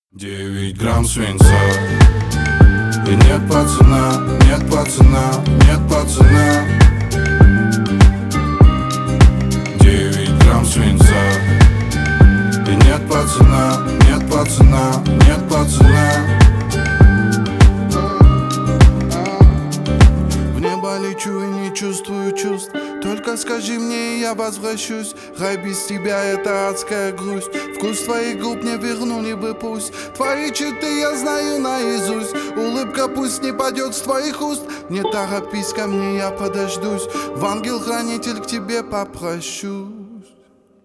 бесплатный рингтон в виде самого яркого фрагмента из песни
Рэп и Хип Хоп